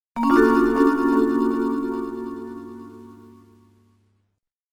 Colonne sonore che fanno parte del gioco.